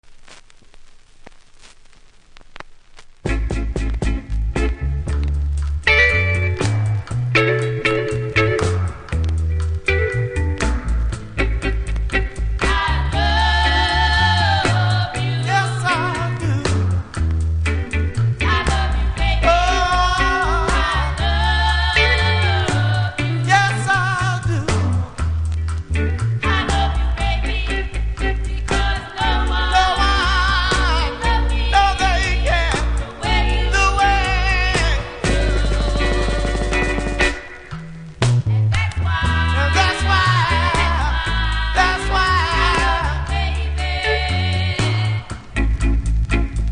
見た目は綺麗ですが、プレス起因で出だしノイズありますので試聴で確認下さい。